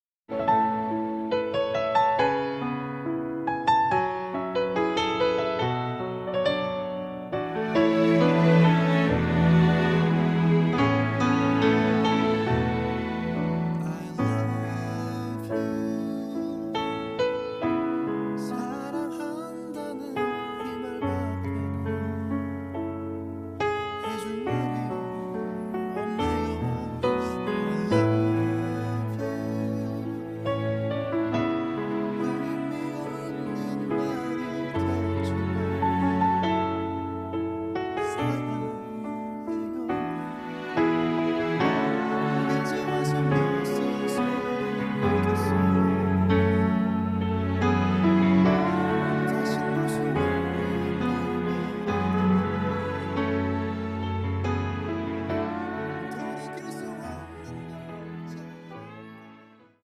음정 -1키 4:42
장르 가요 구분 Voice Cut